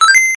Download Ring sound effects in mp3 format for free without login or sign-up and find similar sounds at Quick Sounds library.